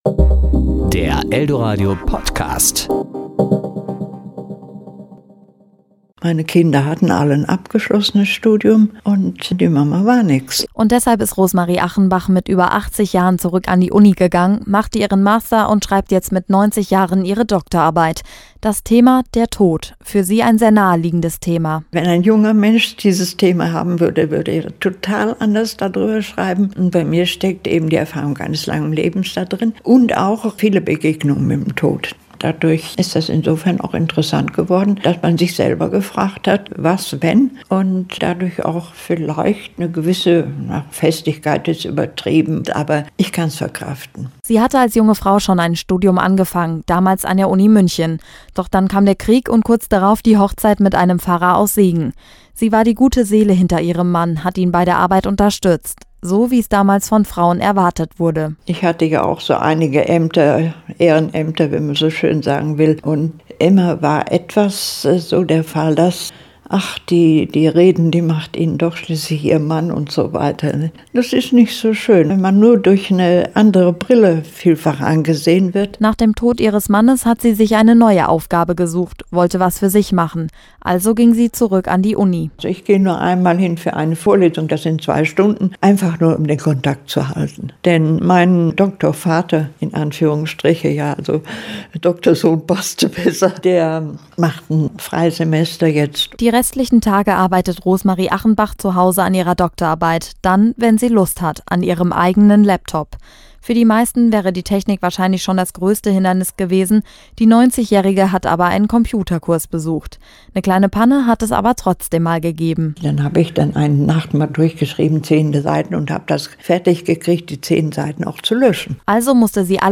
Beiträge  Ressort: Wort  Sendung